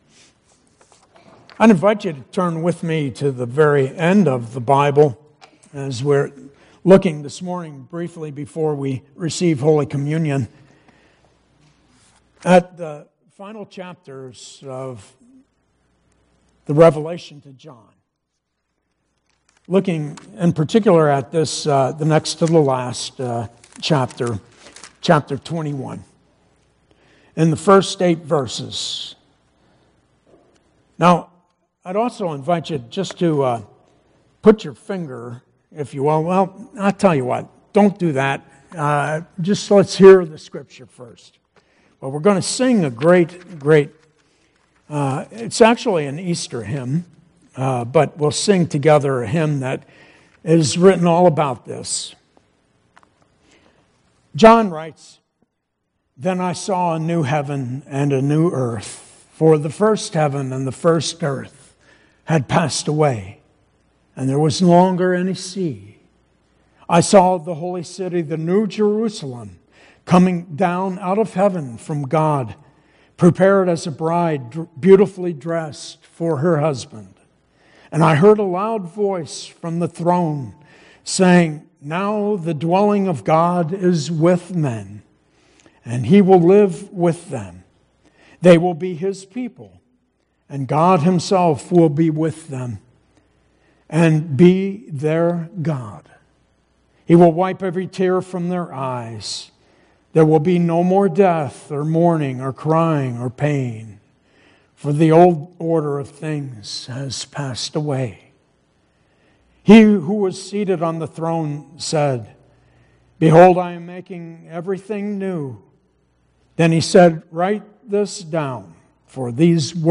Due to recording limitations for the sermon the singing was edited out but can be heard on YouTube.